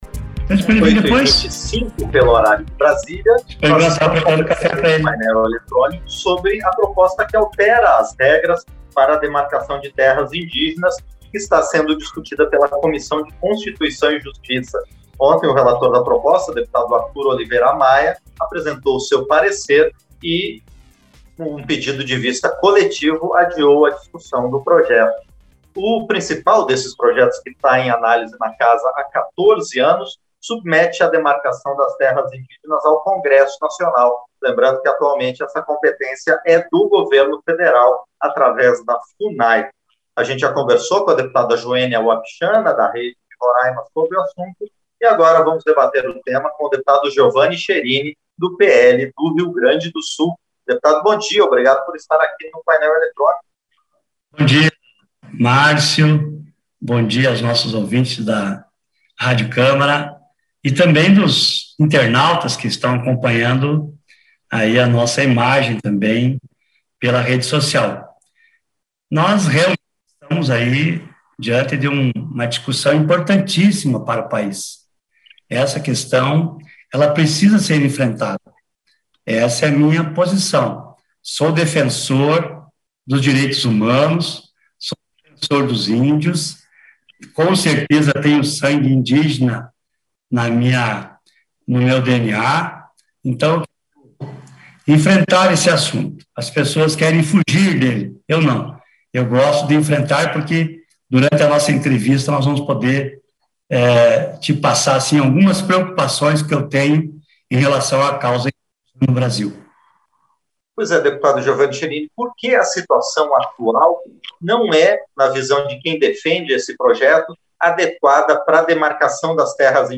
Entrevista - Dep. Giovani Cherini (PL-RS)